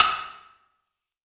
SOUTHSIDE_percussion_clack.wav